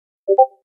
Discordのサウンド、pingメッセージをダウンロードする 無料ダウンロードとオンライン視聴はvoicebot.suで